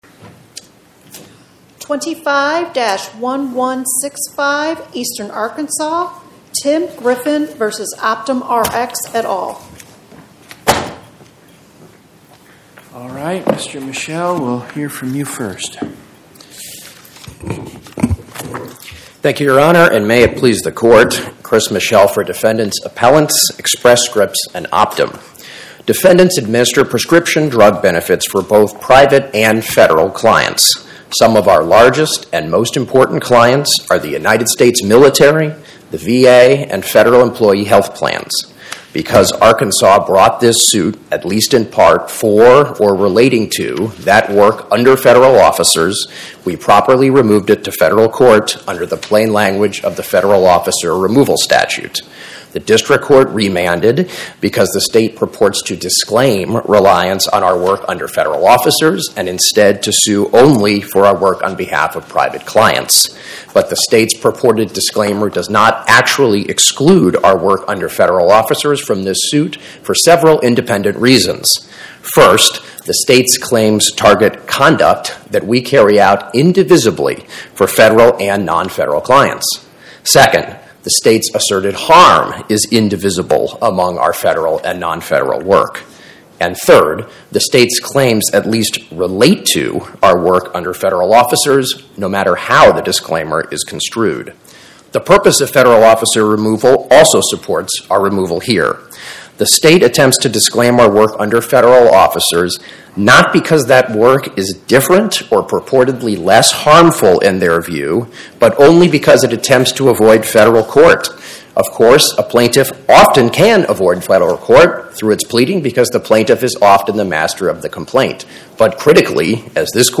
My Sentiment & Notes 25-1165: Tim Griffin vs OptumRx, Inc. Podcast: Oral Arguments from the Eighth Circuit U.S. Court of Appeals Published On: Thu Nov 20 2025 Description: Oral argument argued before the Eighth Circuit U.S. Court of Appeals on or about 11/20/2025